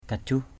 /ka-ʥuh˨˩/ aiek: ‘jajuh’ jj~H